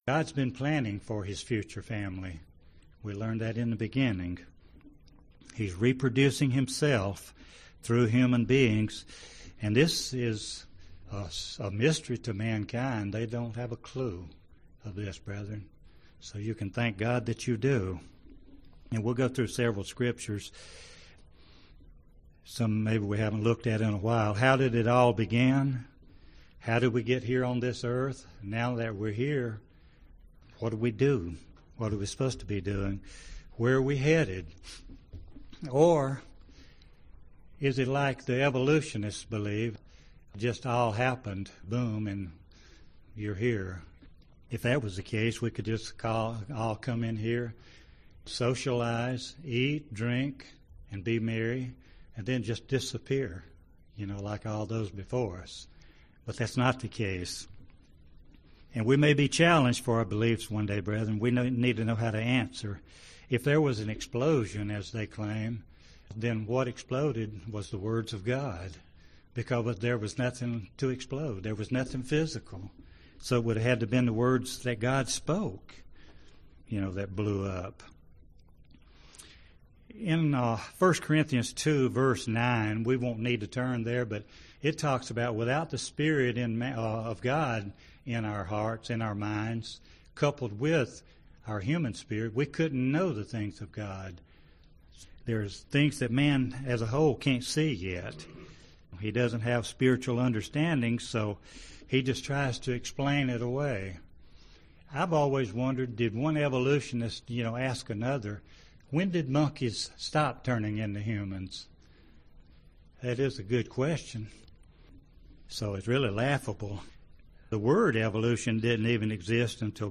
Given in Gadsden, AL